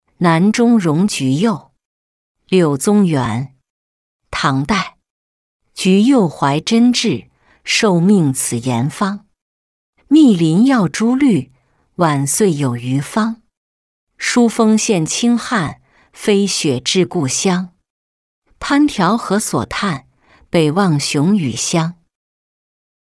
南中荣橘柚-音频朗读